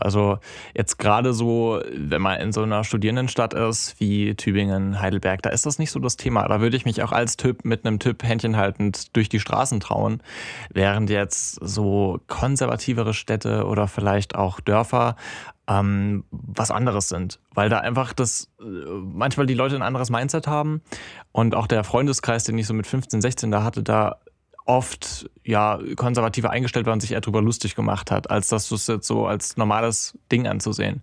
Teaser_Sendung_420_katzentisch192.mp3